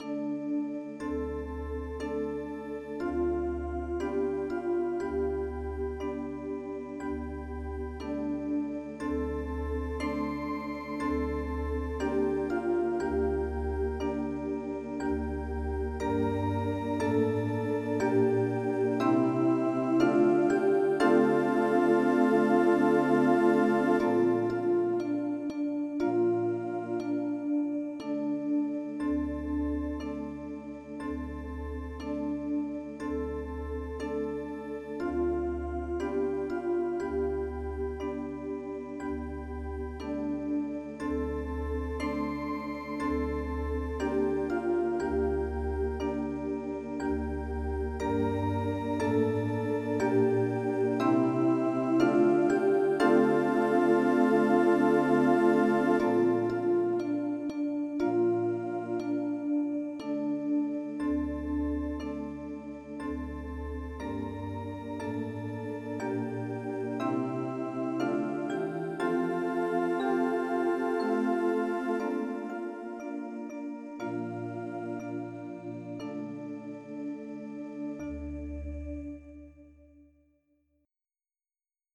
Georgian MID Songs for Children